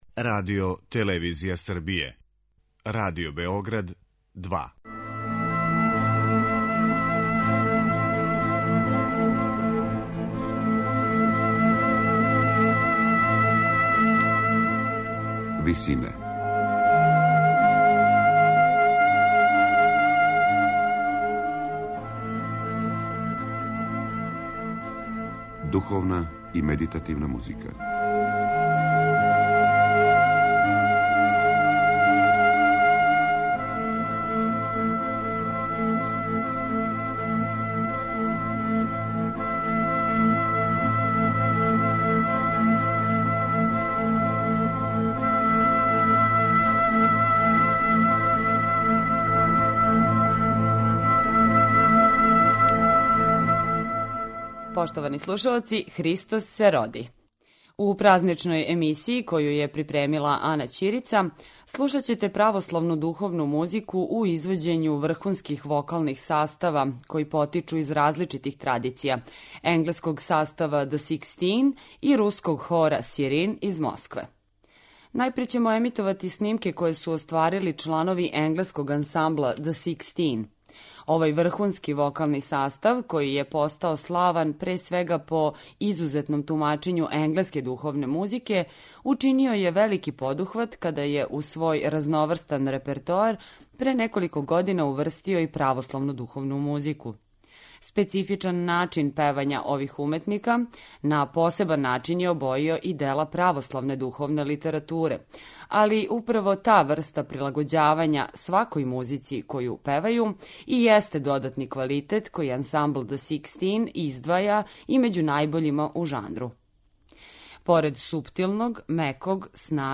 Православна духовна музика